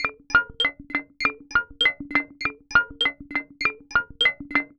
tx_synth_100_hardbloops2.wav